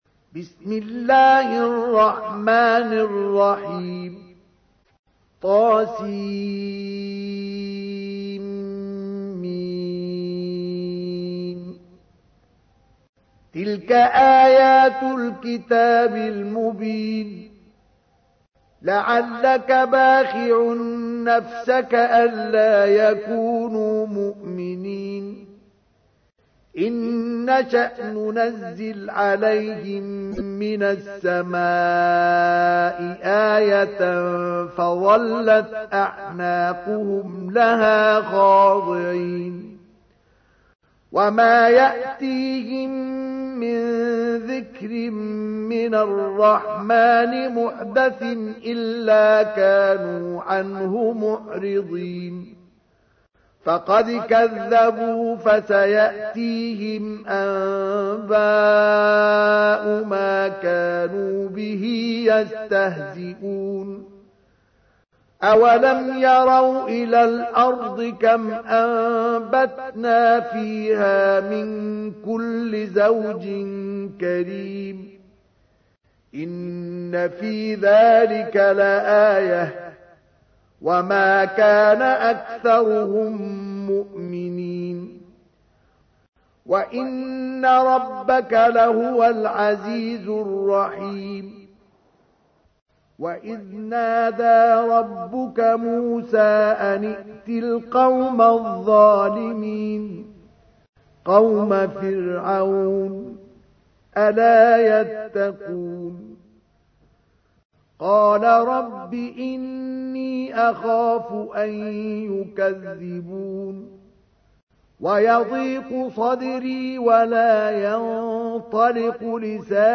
تحميل : 26. سورة الشعراء / القارئ مصطفى اسماعيل / القرآن الكريم / موقع يا حسين